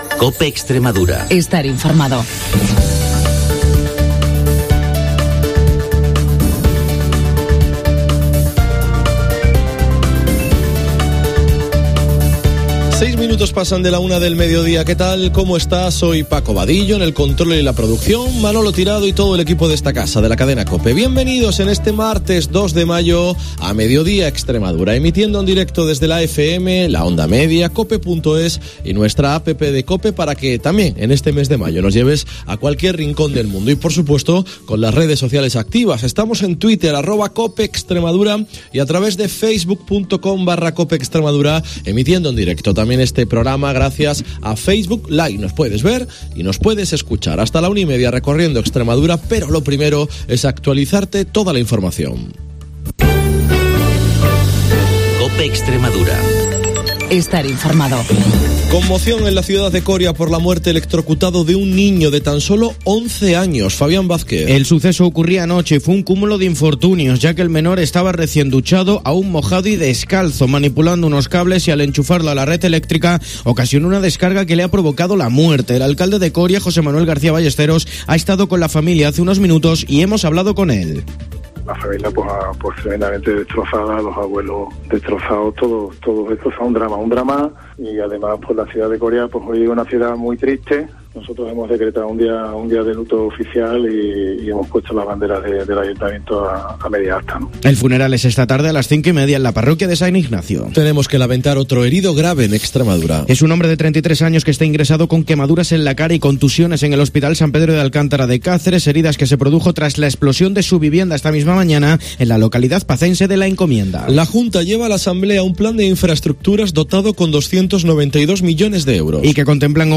Hoy hemos compartido con Iberdoex Extremadura un homenaje a un taxista que lleva 27 años de profesión y a quien la empresa extremeña le ha querido homenajear en directo.